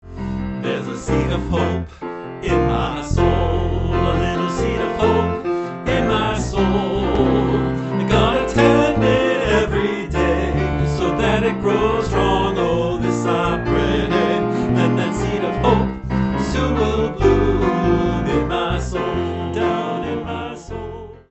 Zipper Song